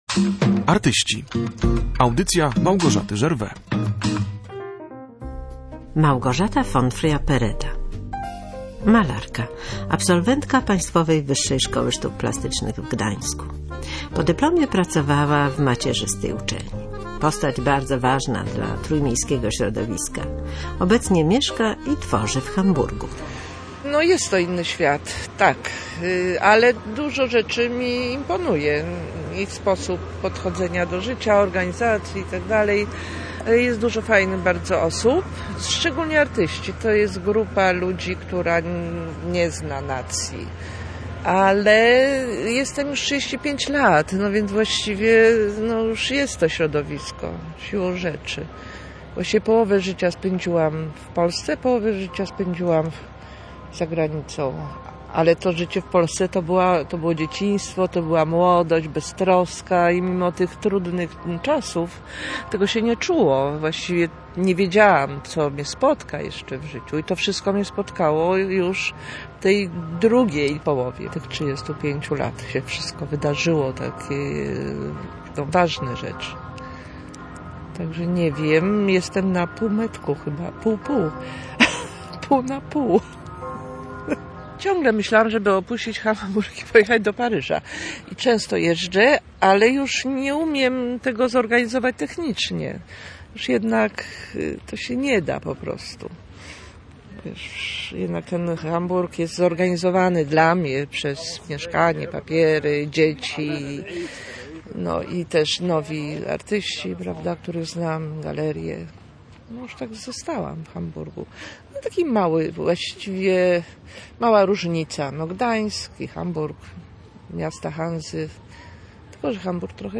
Było to okazją do rozmowy o emigracji, o malowaniu, o podporządkowaniu życia twórczości i o przemijaniu.